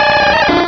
pokeemerald / sound / direct_sound_samples / cries / squirtle.aif